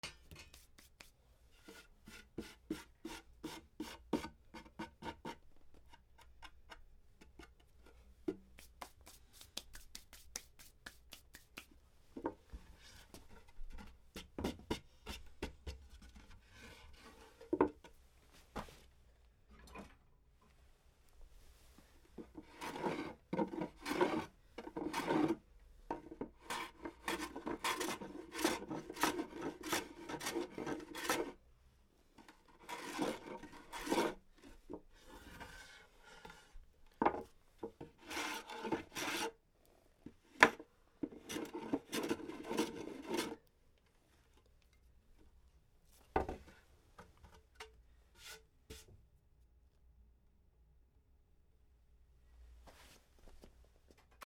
木工工作